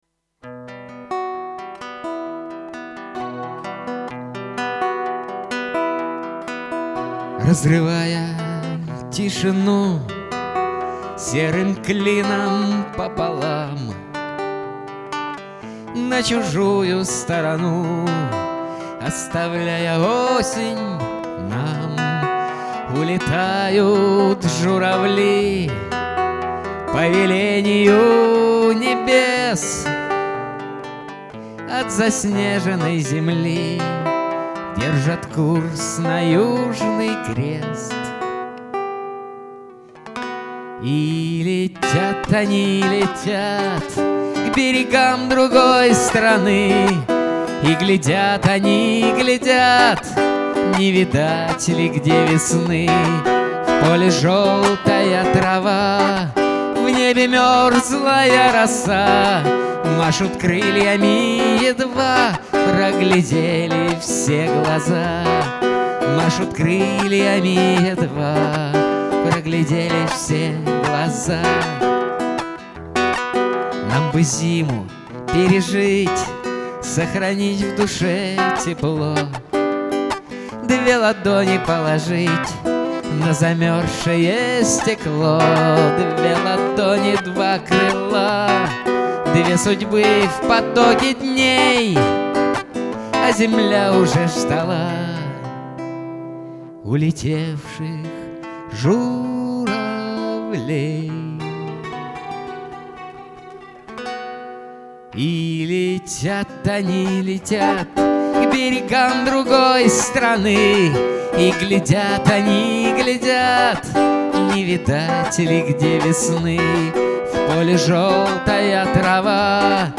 Гитара / Лирические
Слушать на гитаре